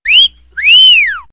silbido_a.wav